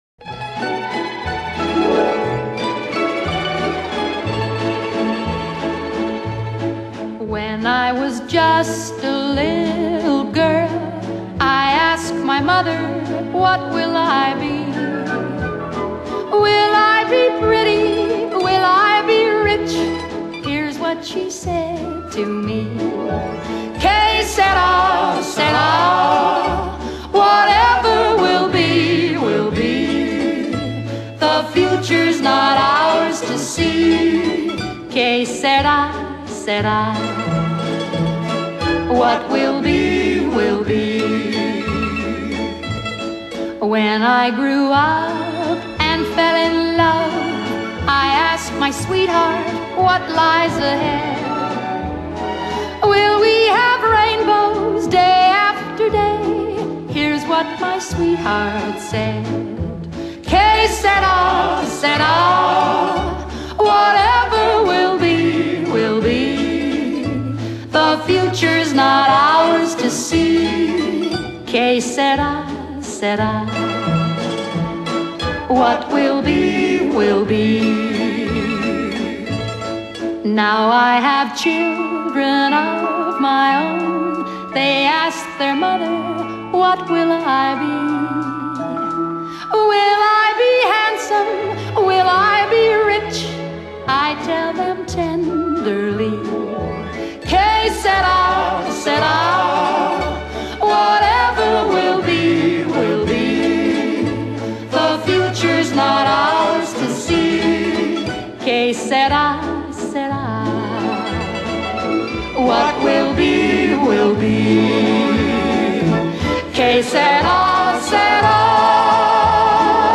Bluegrass, Folk-Punk, Rock'n'Roll